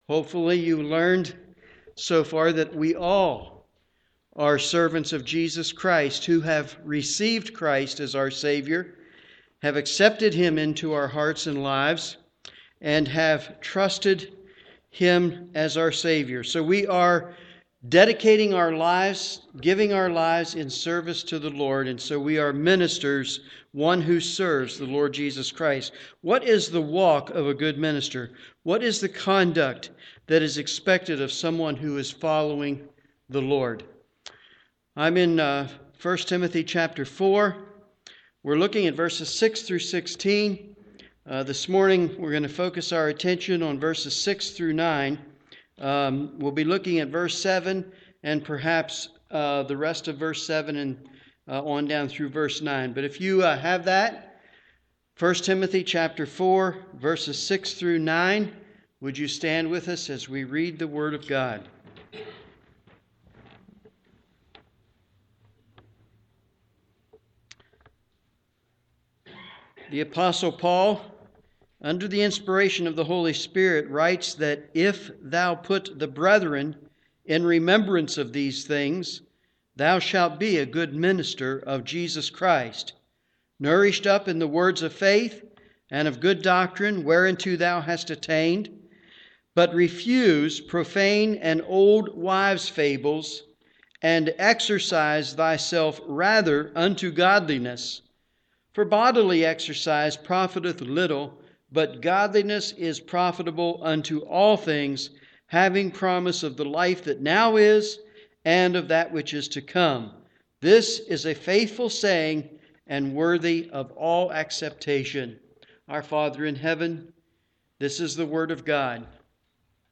Morning Worship
Sermon